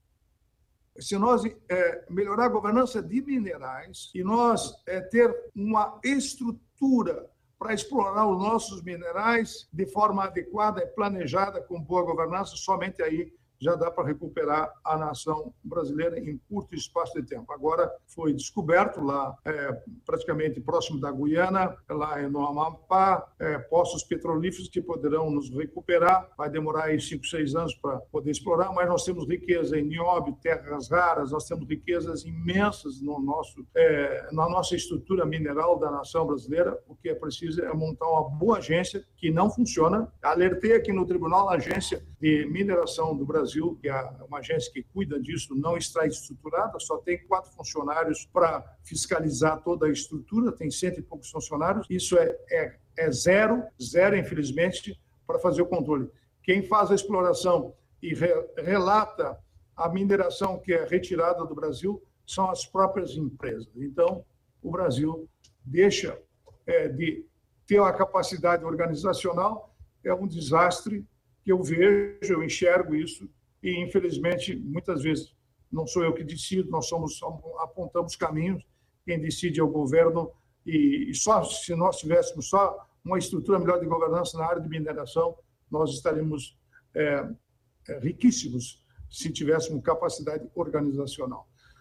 02-Ministro-prog-radio-mineracao-sem-capacidade-organizacional.mp3